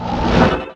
spawners_mobs_teleport.ogg